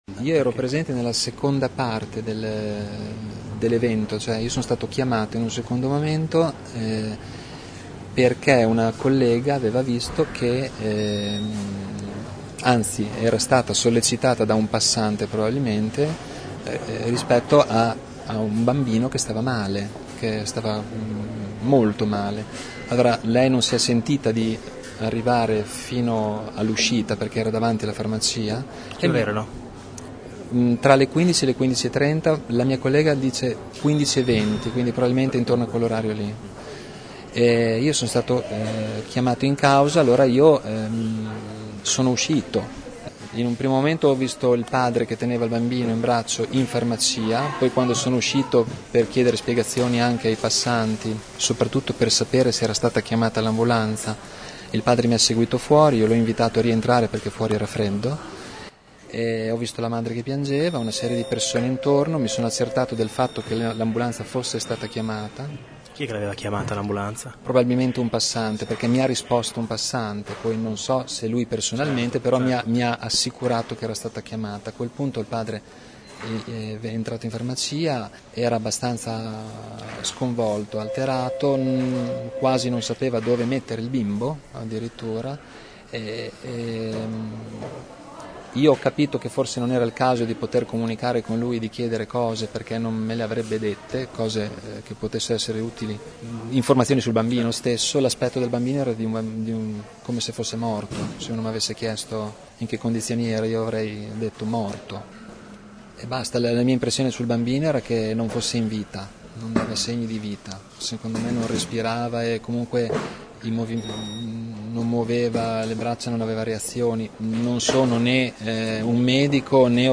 Ascolta la testimonianza del farmacista